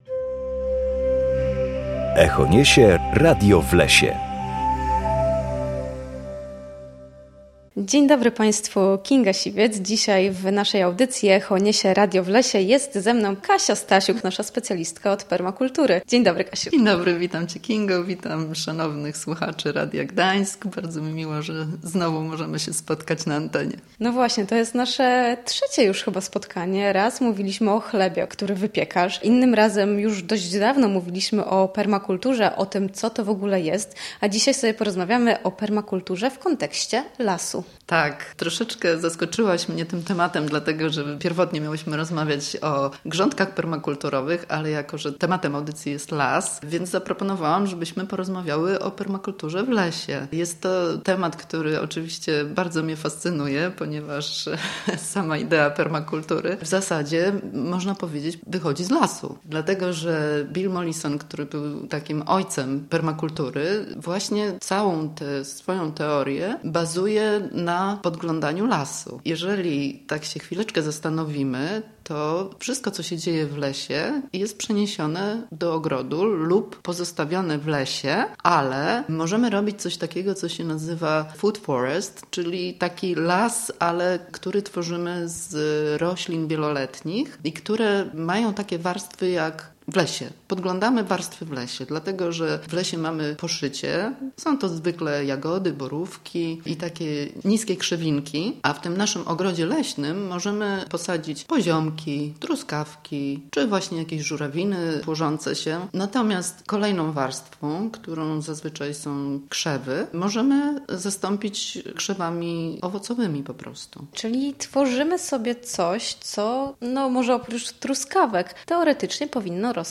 Dlaczego warto odpuścić sobie grabienie ogrodu? Odpowiada specjalistka od permakultury [ECHO NIESIE RADIO W LESIE]